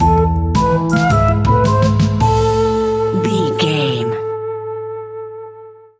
Aeolian/Minor
scary
ominous
eerie
playful
synthesiser
drums
horror music